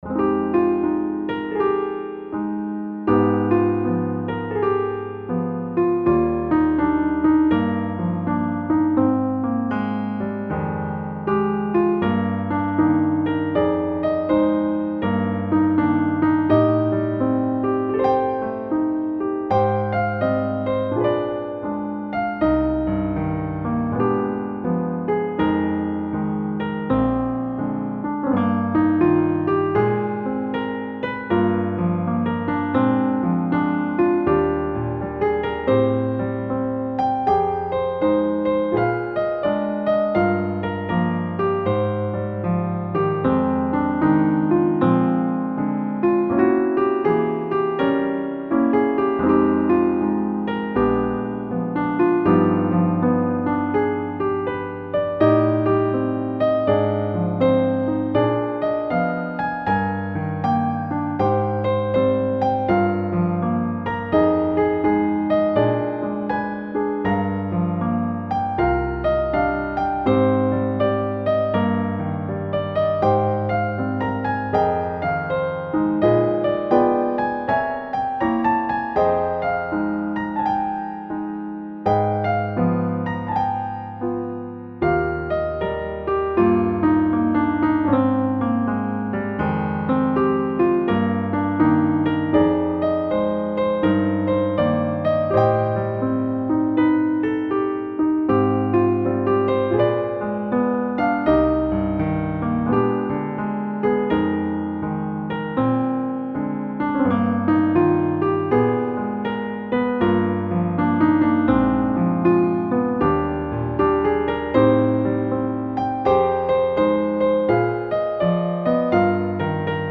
I always have my recording level set at -07'.